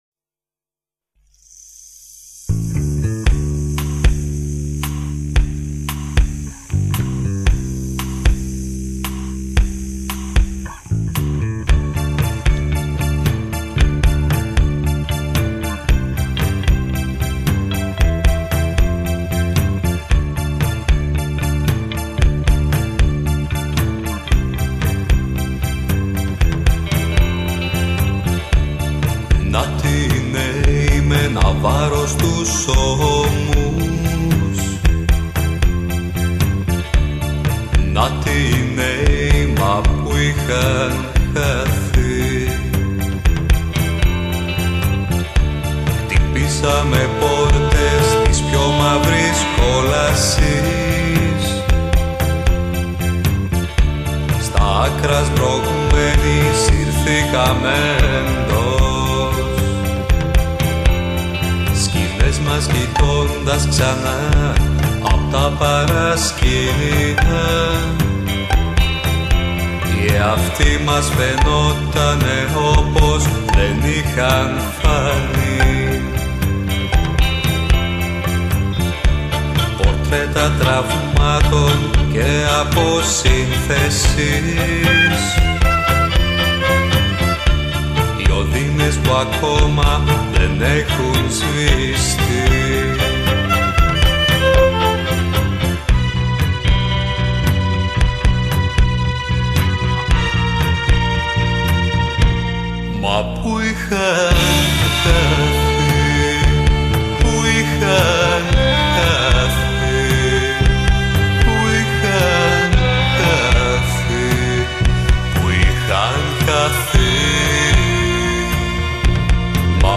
ελληνική διασκευή
greek rock version